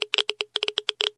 geiger_7.ogg